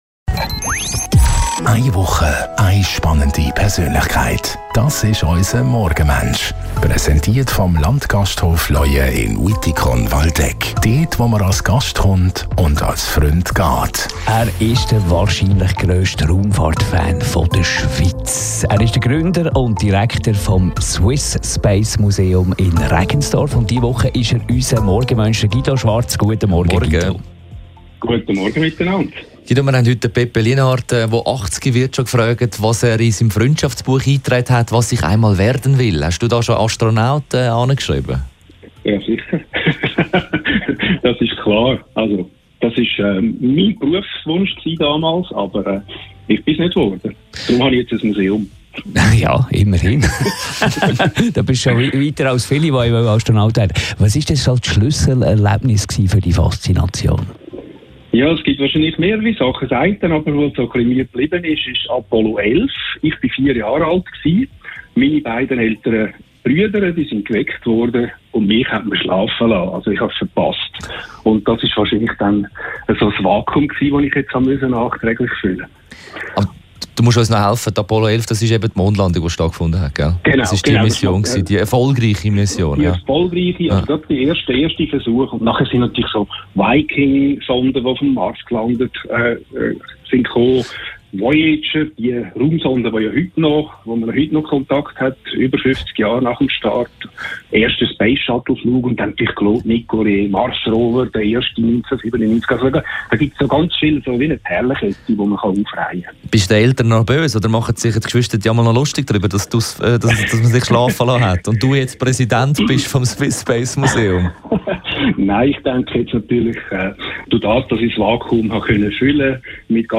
telefonieren jeden Morgen von Montag bis Freitag nach halb 8 Uhr mit einer interessanten Persönlichkeit.